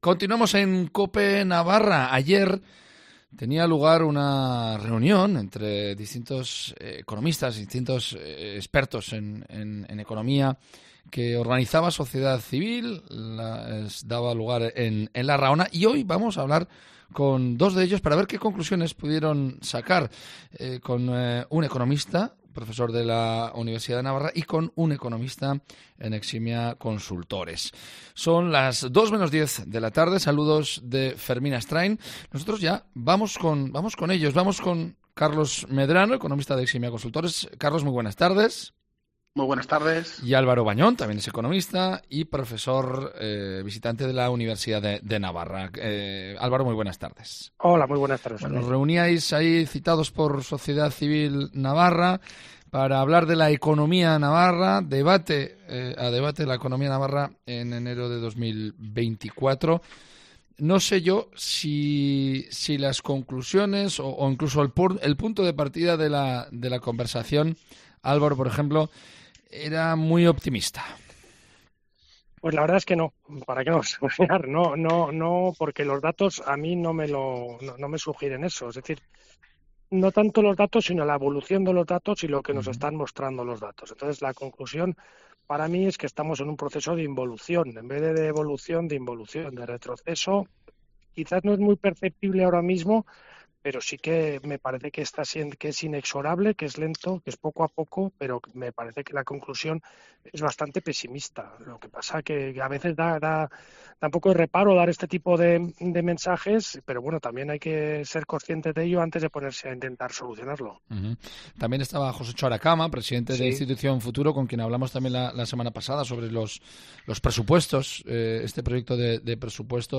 AUDIO: Sociedad Civil Navarra organizó el lunes 22 de enero una conferencia para analizar la economía en Navarra.